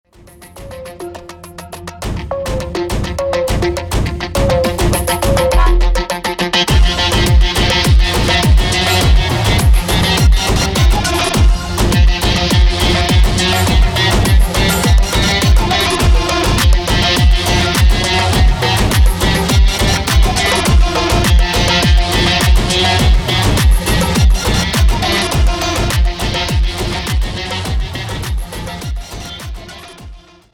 • Type : Instrumental
• Bpm : Allegro
• Genre : Techno/ Metal